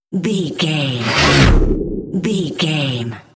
Scifi whoosh pass by
Sound Effects
futuristic
pass by
vehicle